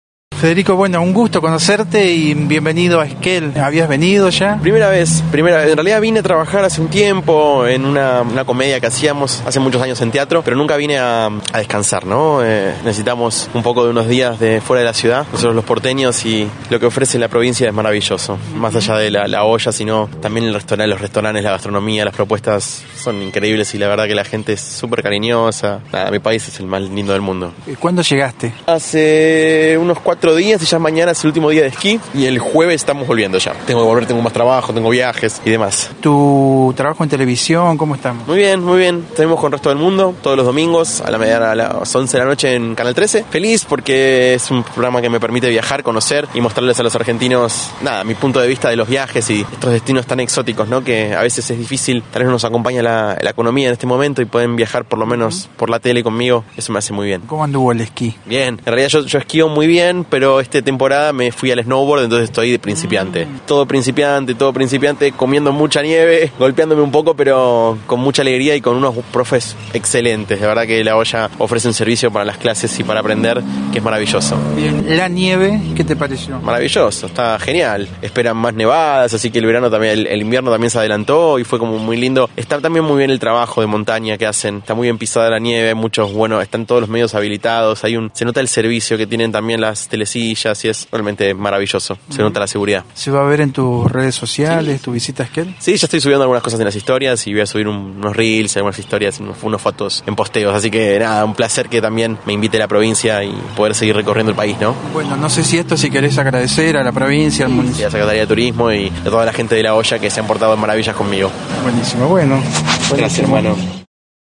El actor y conductor Federico Bal se encuentra de vacaciones en Esquel, donde disfruta de la nieve que ofrece el centro de Esquí La Hoya. De muy buen ánimo dialogó con Noticias de Esquel sobre su visita a la zona.